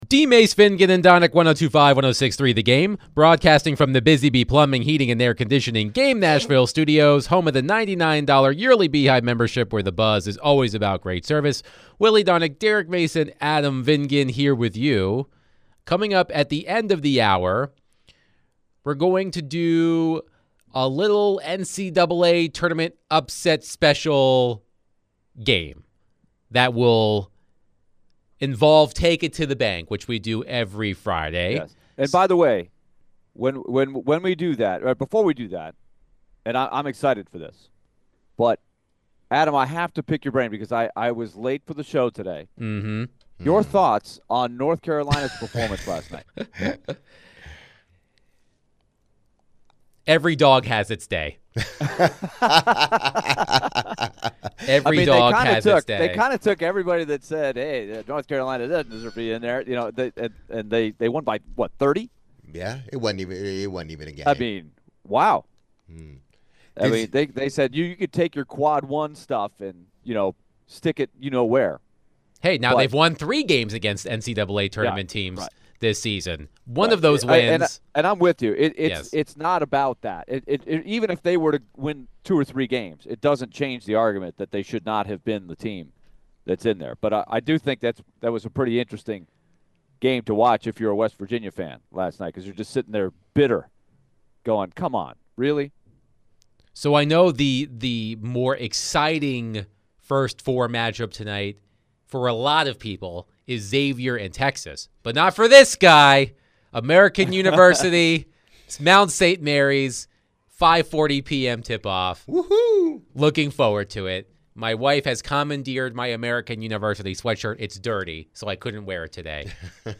Later in the hour, the Guys answered more calls about the Titans and the NFL.